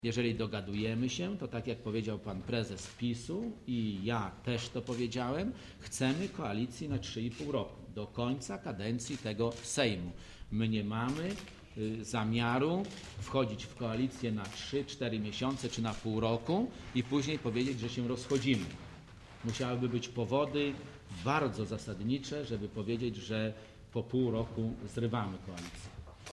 Posłuchaj wypowiedzi Andrzeja Leppera o koalicji